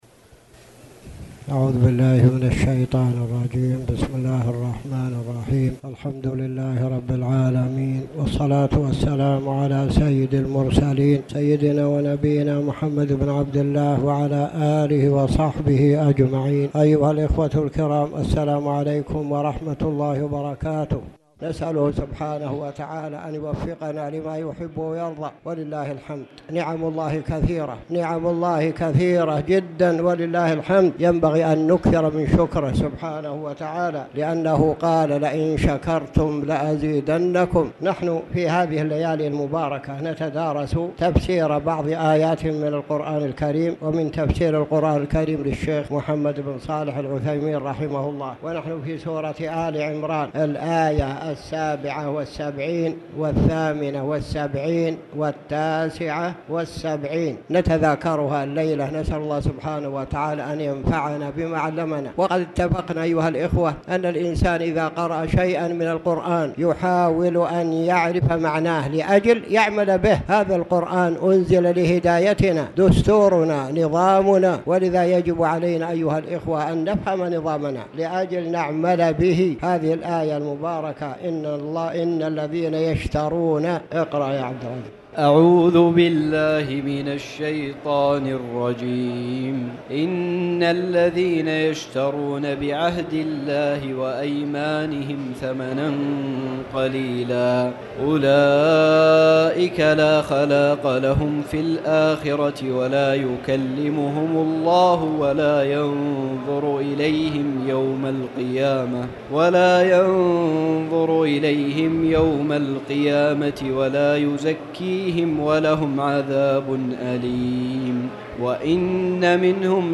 تاريخ النشر ١٦ رمضان ١٤٣٨ هـ المكان: المسجد الحرام الشيخ